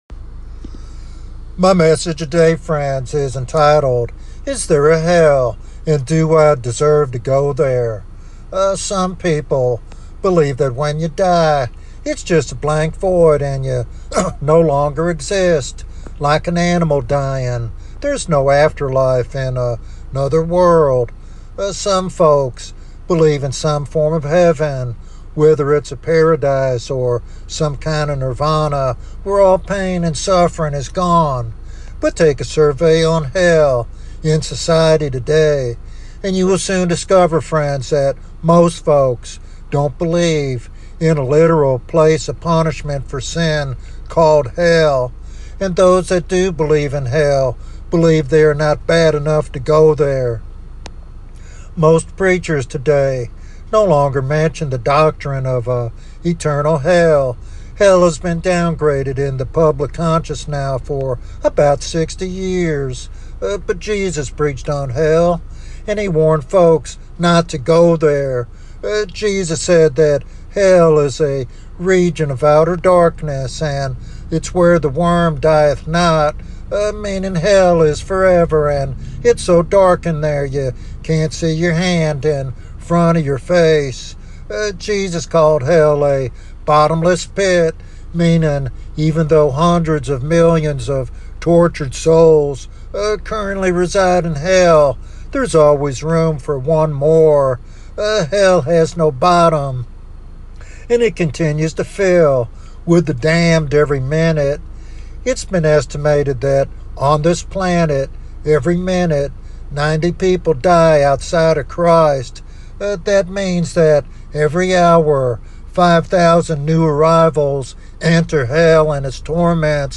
In this compelling evangelistic sermon